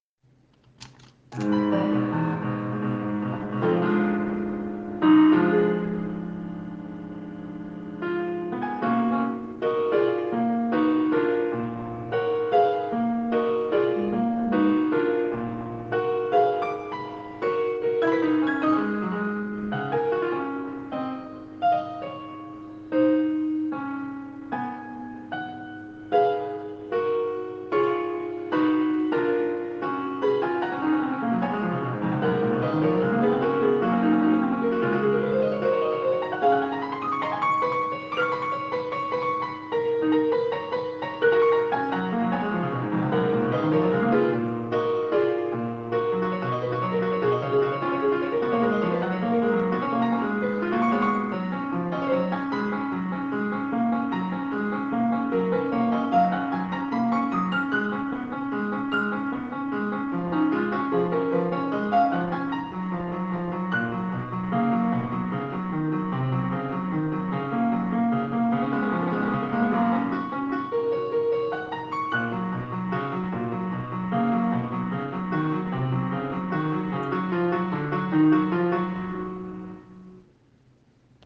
Also, the quality is pretty poor, because I recorded it with my smartphone, to show it some friends.